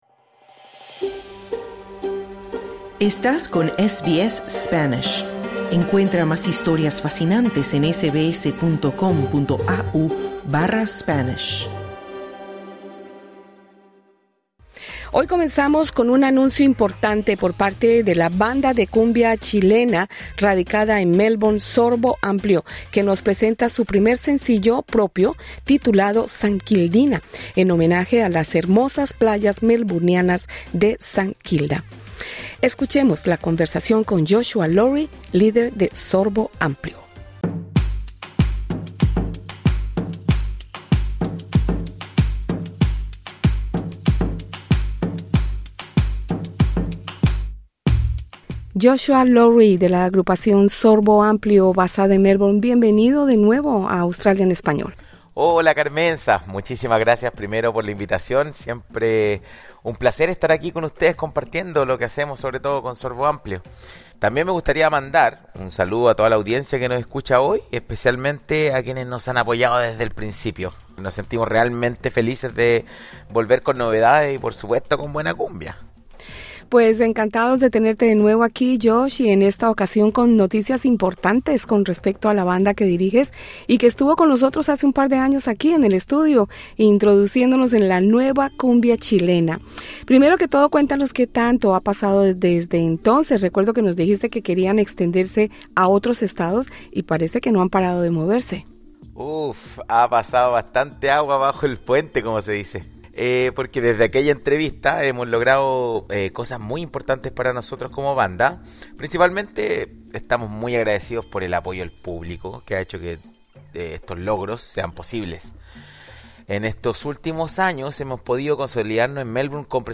Desde su última visita a los estudios de SBS en Melbourne, en 2023, l a agrupación Sorbo Amplio no ha parado de crecer.